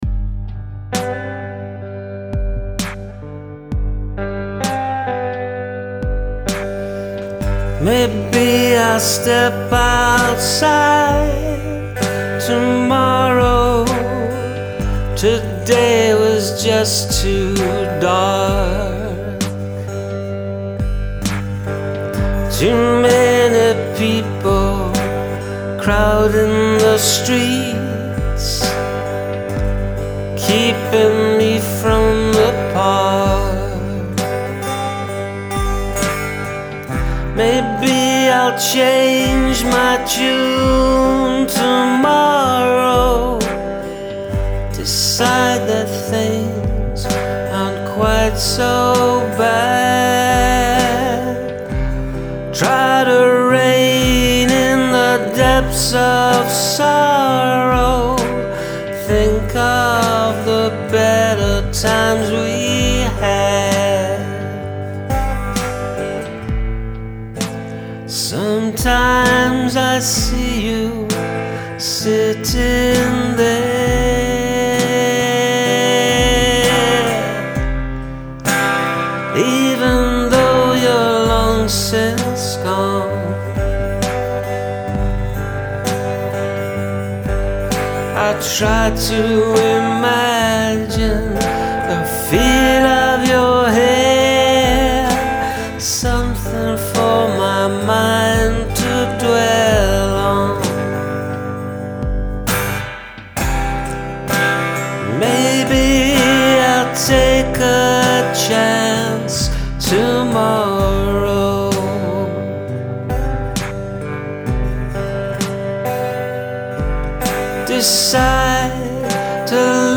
D’Angelico no capo